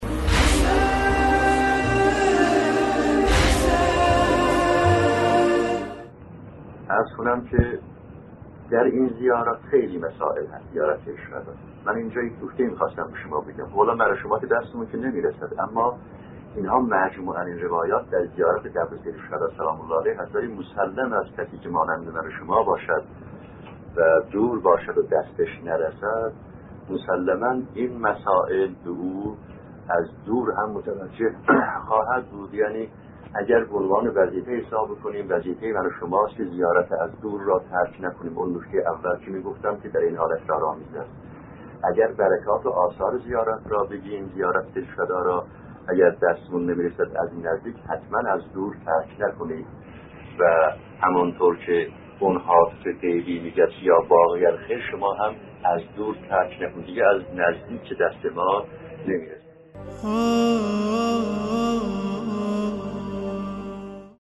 در ادامه، قسمت چهل این سلسله‌گفتار را با عنوان «زیارت از دور سیدالشهدا(ع) را ترک نکنید» می‌شنوید.